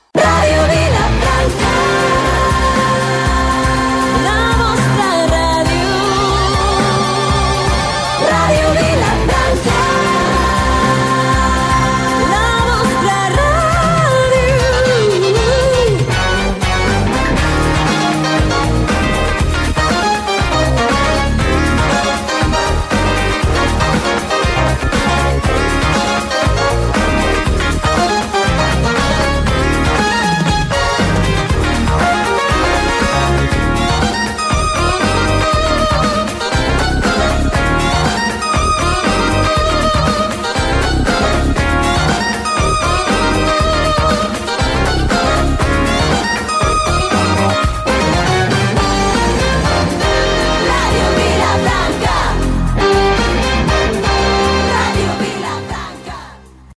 Música identificativa amb identificació de la ràdio